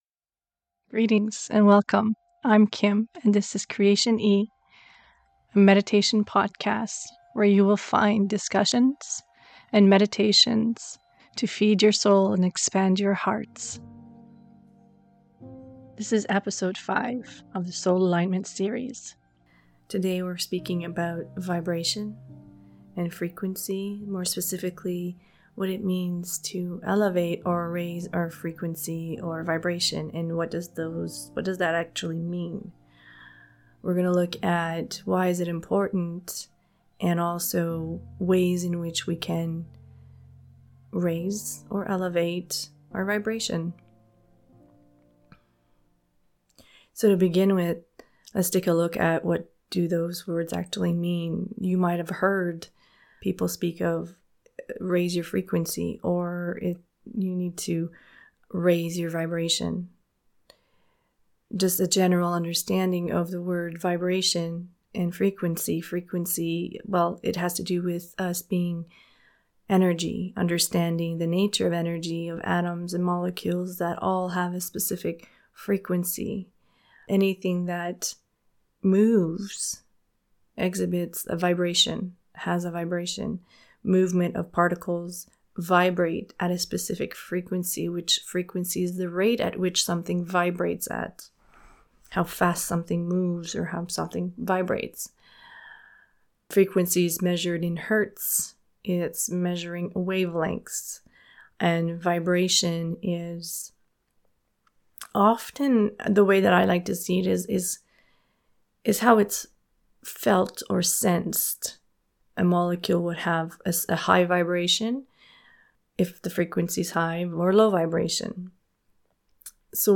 The guided meditation included guides you into a higher state of vibration connecting to your heart energy and your I am presence consciousness.